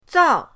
zao4.mp3